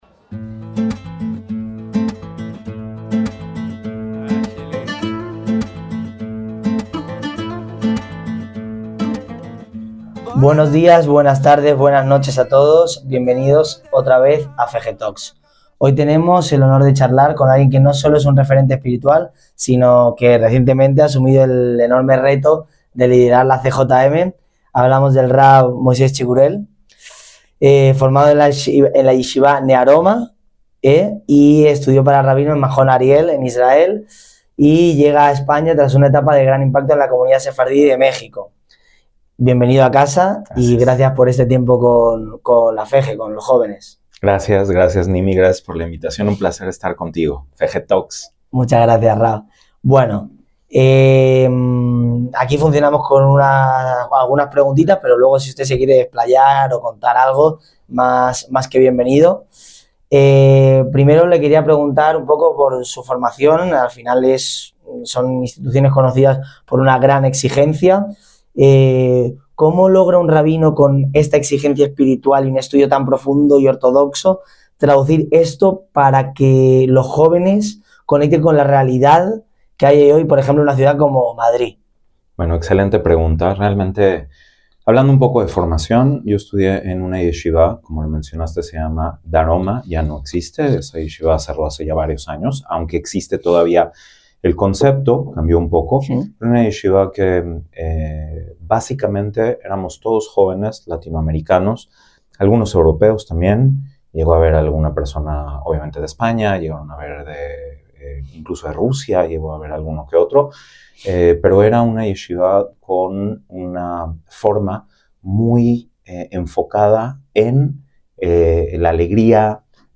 FEJJE TALKS: LA VOZ DE LOS JÓVENES JUDÍOS EN ESPAÑA - En esta entrevista para la FEJJE (Federación de Jóvenes Judíos en España)